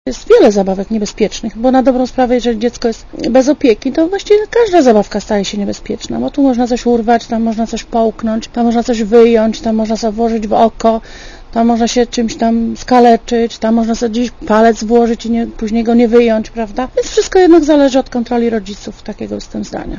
Mówi Cezary Banasiński, szef urzędu